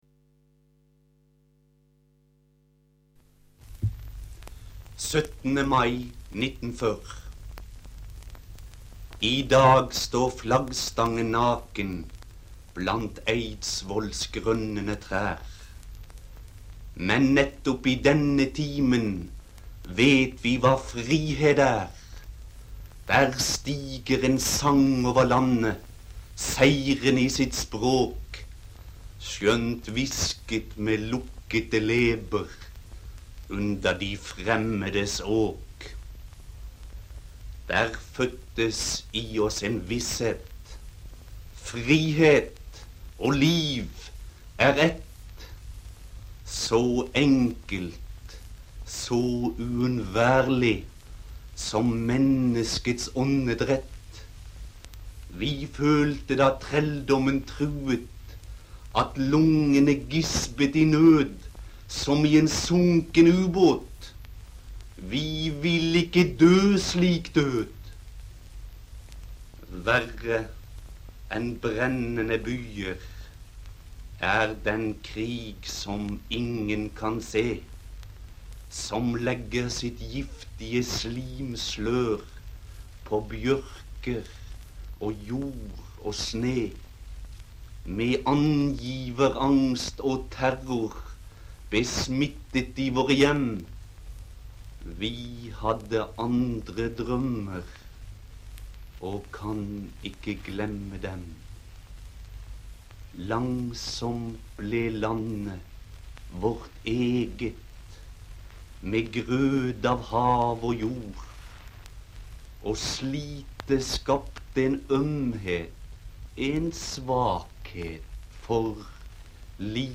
Vi er så heldige at Arnulv Øverland og Nordahl Grieg finnes i opptak lese sine dikt «Du må ikke sover“ og „17. mai 1940“.
Her under kan vi høre Nordahl Grieg lese diktet 17. mai 1940 - og vi kan lese diktet på islandsk.